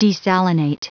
Prononciation du mot desalinate en anglais (fichier audio)
Prononciation du mot : desalinate